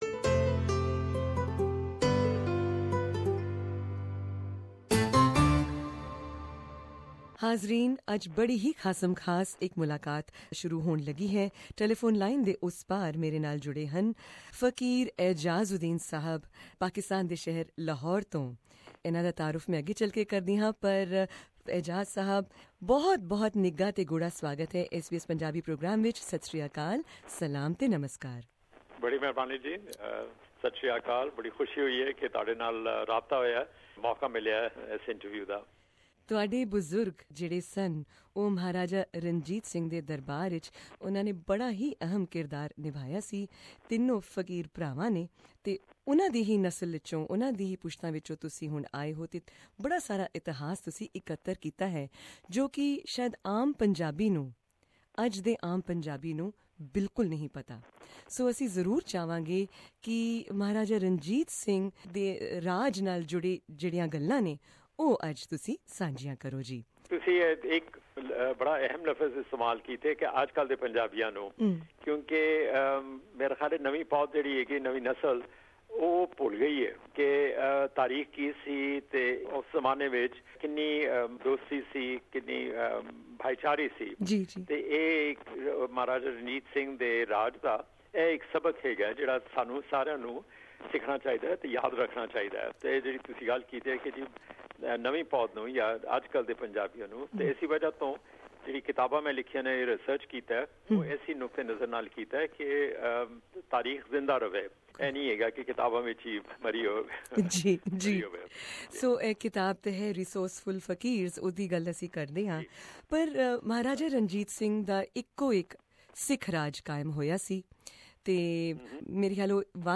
In an extensive interview with SBS Punjabi